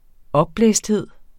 Udtale [ ˈʌbˌblεˀsdˌheðˀ ]